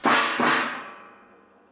trashcan
boingy crash dustbin lid metal sound effect free sound royalty free Memes